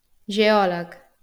wymowa:
IPA[ʒeˈɔlək]